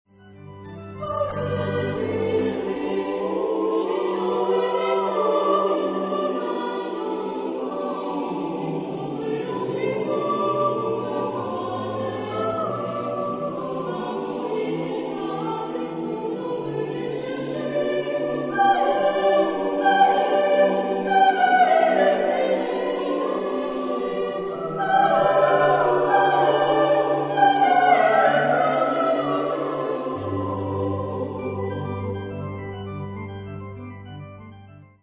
Genre-Stil-Form: Weihnachtslied ; Carol
Chorgattung: SATTBB  (6-stimmiger gemischter Chor )
Tonart(en): G-Dur